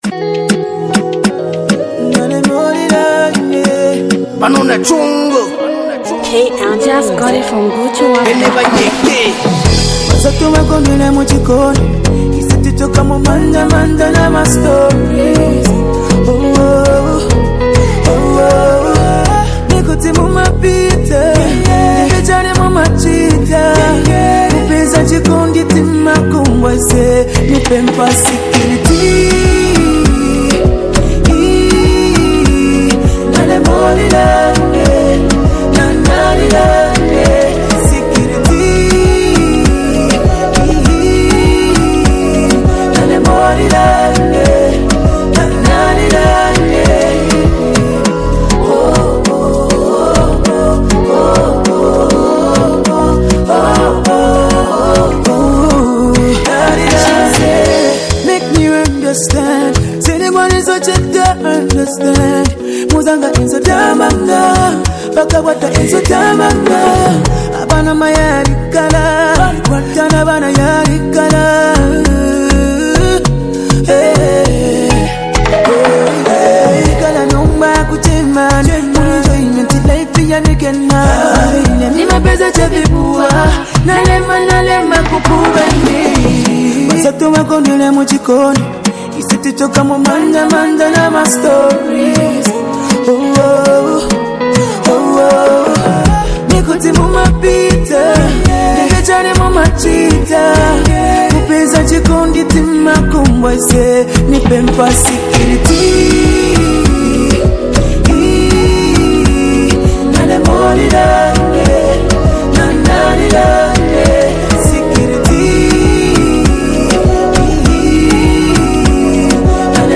Zambian Mp3 Music
powerful melodic sound